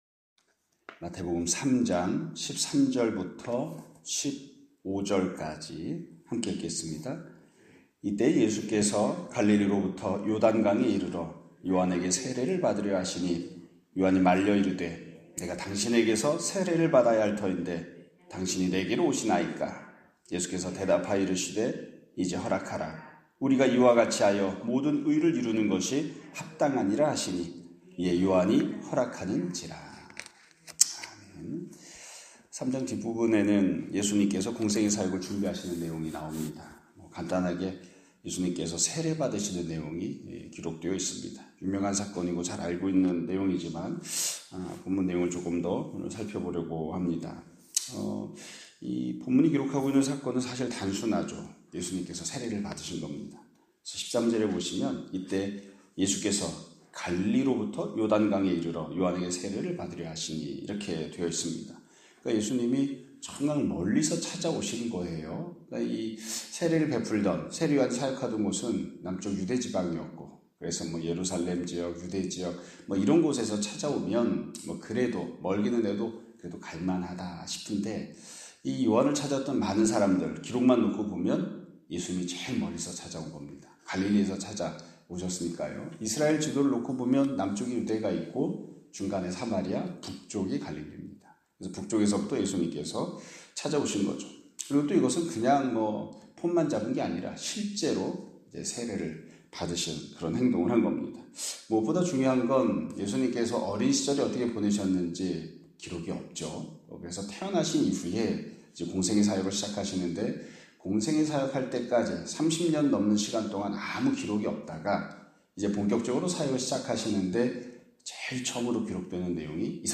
2025년 4월 17일(목요일) <아침예배> 설교입니다.